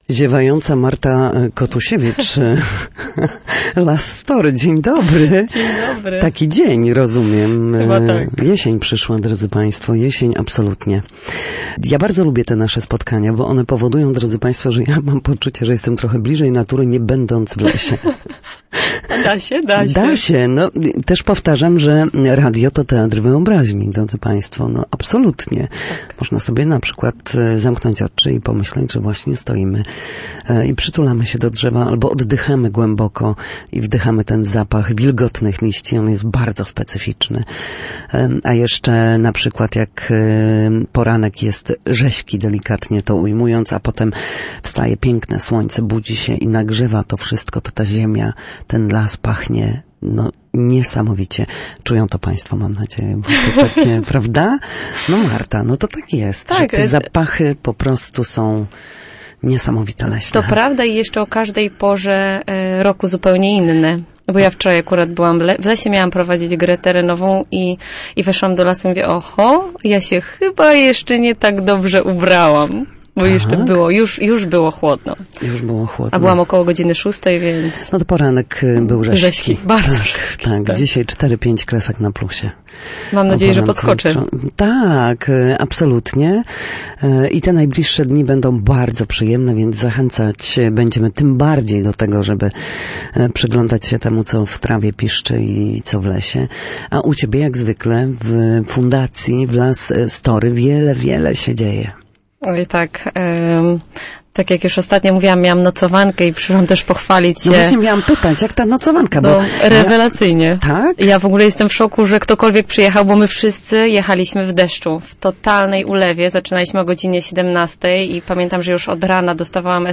Nocowanki, leśne warsztaty, projekty i urodziny Fundacji Las Story. W Studiu Słupsk